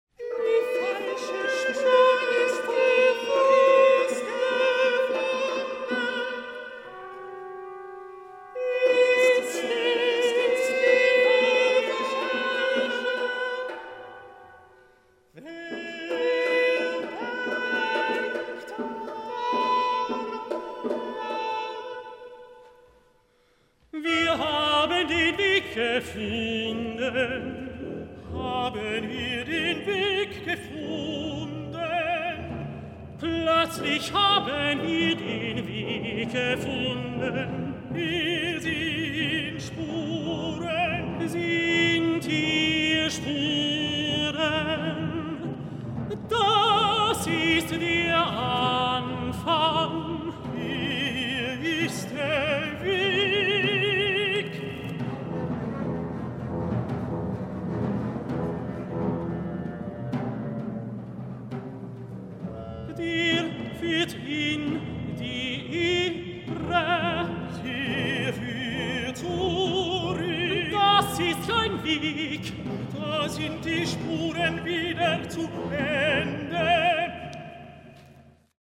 chamber opera
A meditative, almost Zen work.